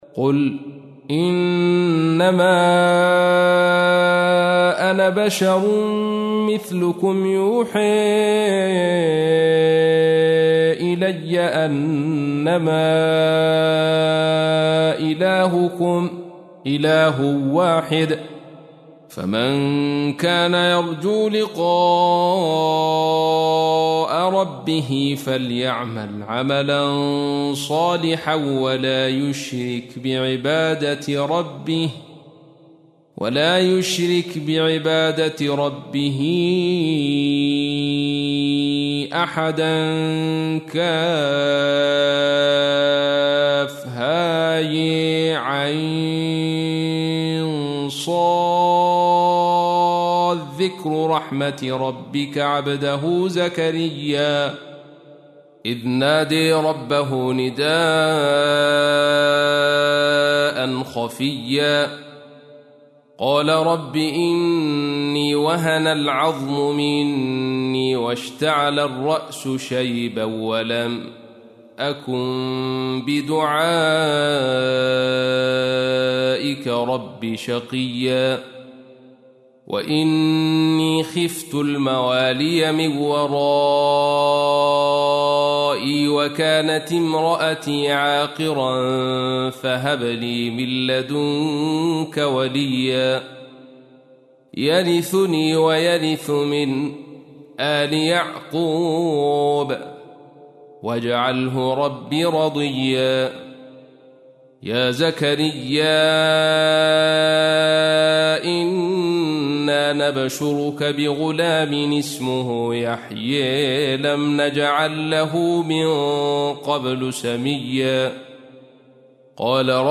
تحميل : 19. سورة مريم / القارئ عبد الرشيد صوفي / القرآن الكريم / موقع يا حسين